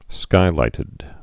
(skīlītĭd)